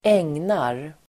Uttal: [²'eng:nar]